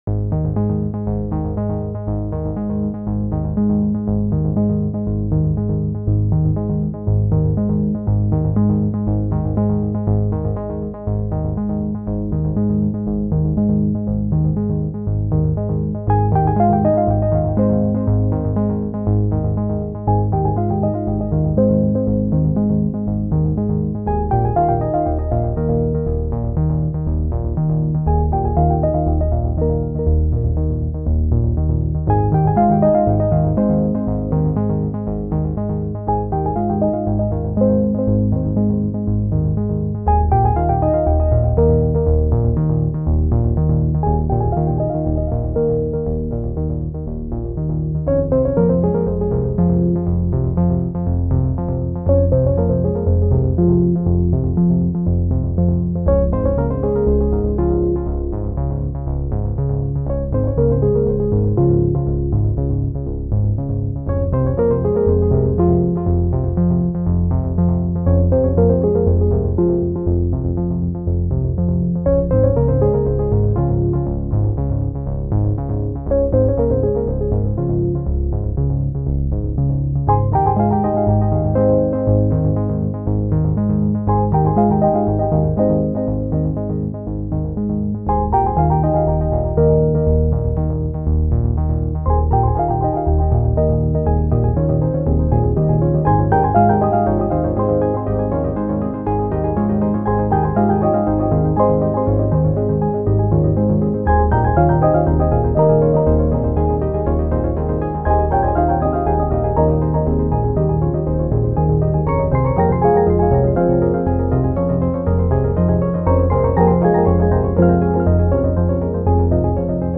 Tags: CC by, Linux, Neueinsteiger, ohne Musik, screencast, SteamOS